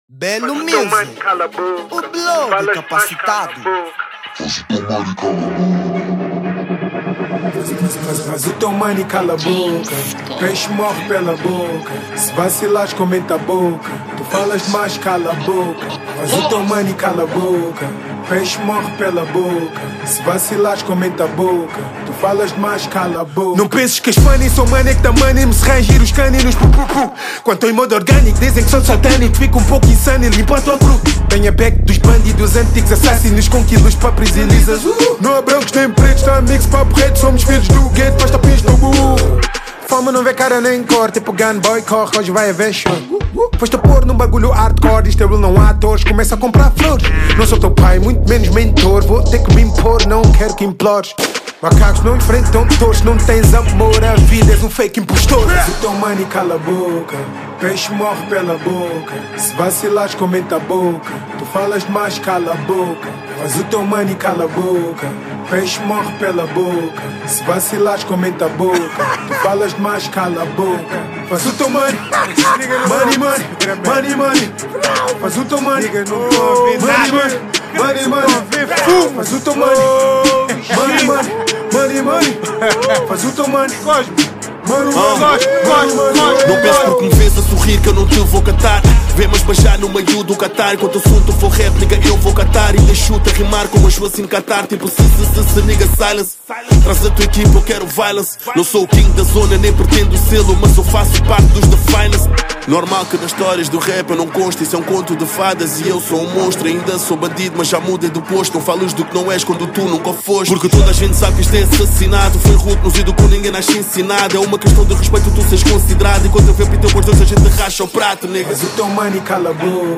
Género : Rap